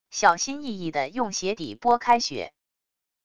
小心翼翼的用鞋底拨开雪wav音频